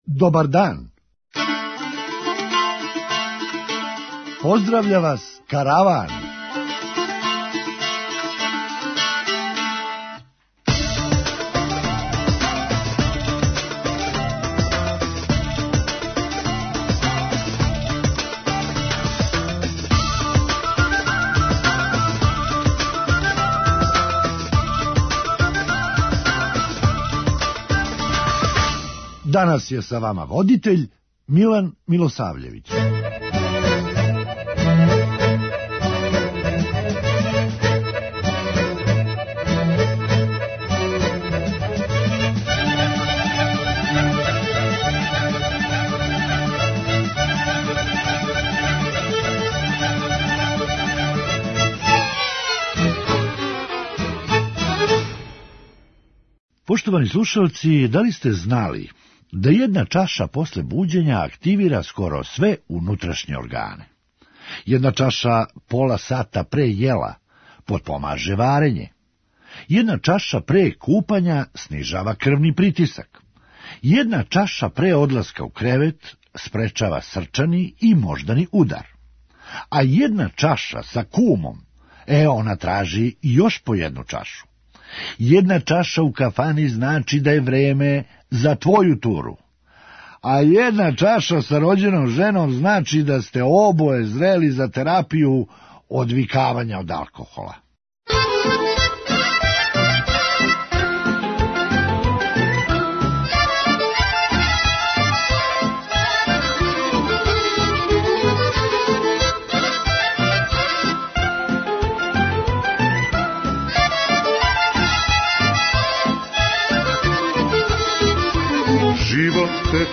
Караван: Хумористичка емисија